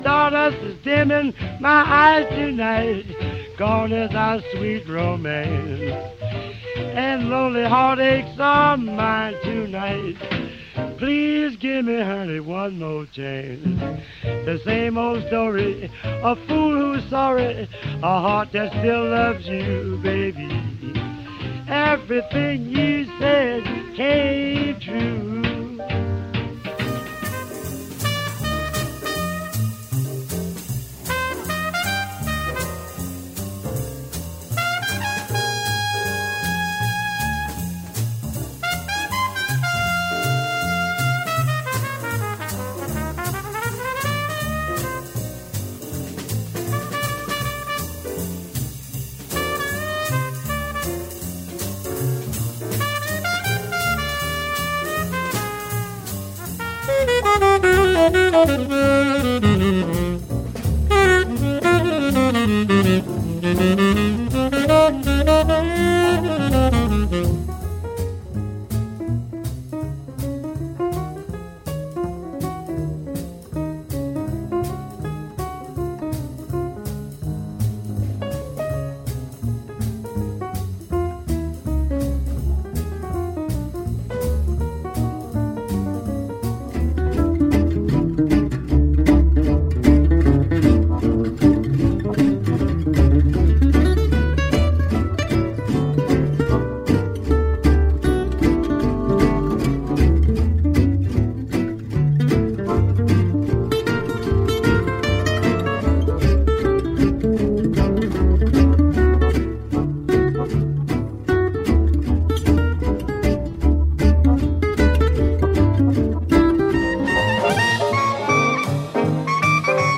Mix of Jazz and Trip Hop Music